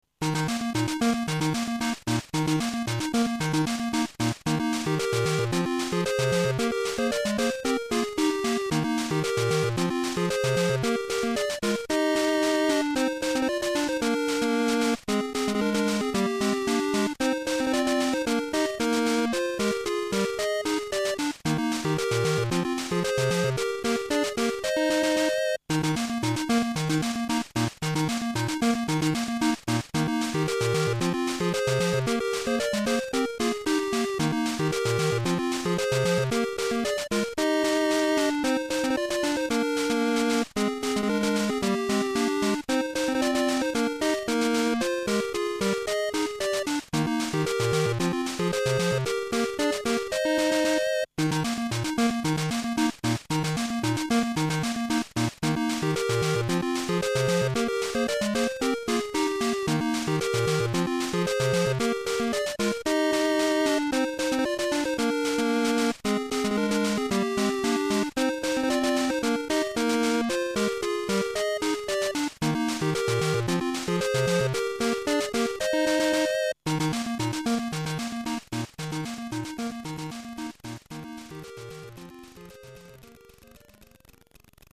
※データは全てSC-88Pro専用です